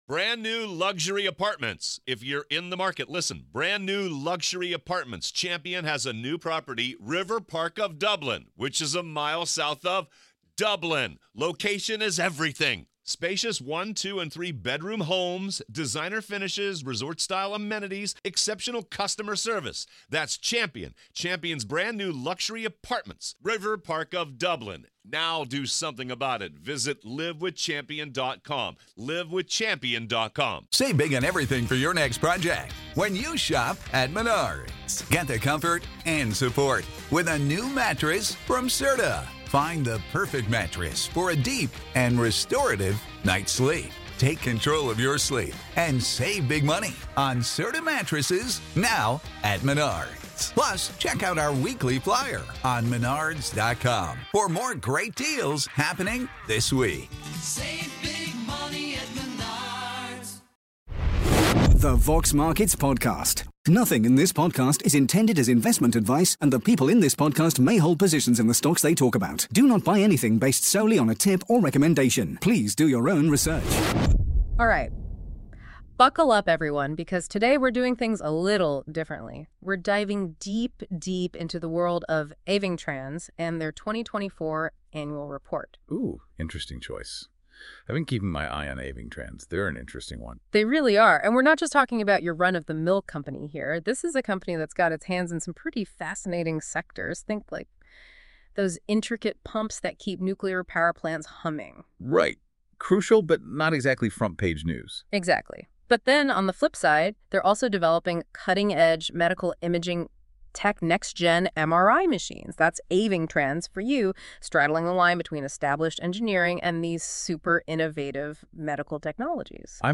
In this episode, powered by ai, we explore Avingtrans' 2024 annual report. We break down their "Pinpoint, Invest, Exit" (PIE) strategy, record-breaking revenue, and why profits took a dip.